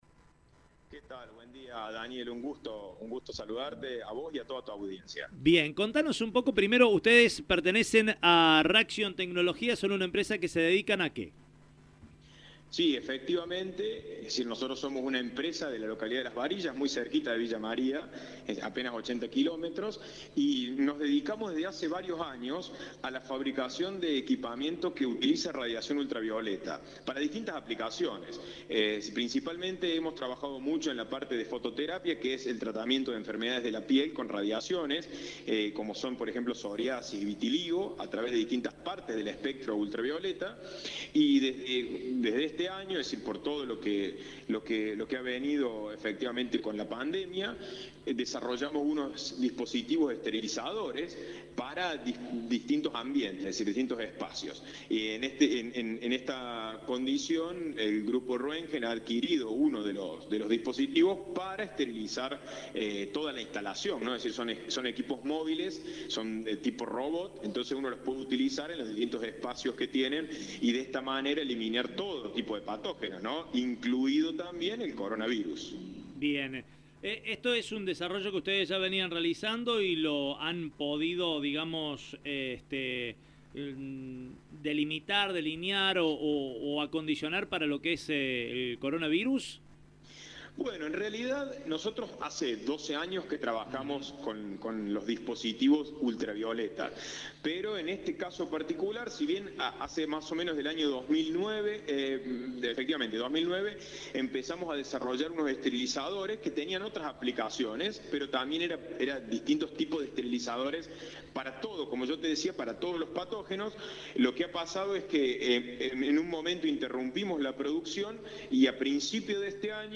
dialogó con nuestra emisora y contó todos los pormenores de la reciente innovación.